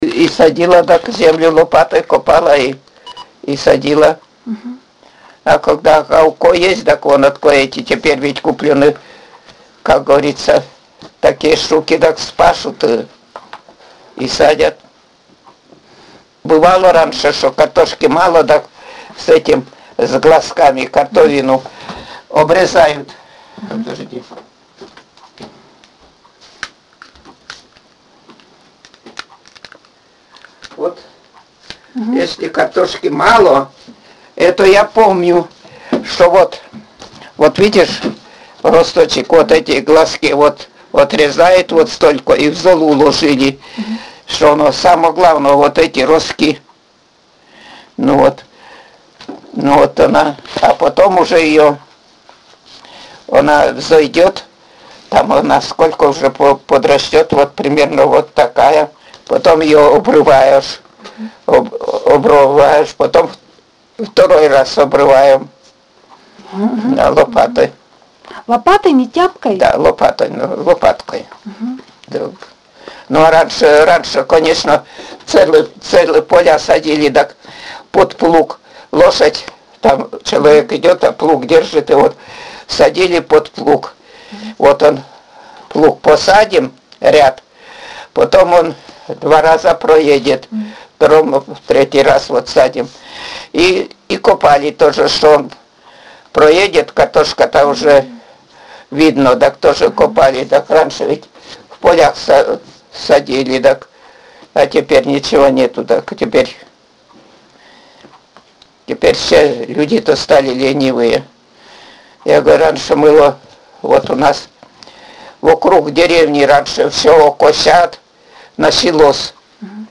— Говор северной деревни
Пол информанта: Жен.
Аудио- или видеозапись беседы: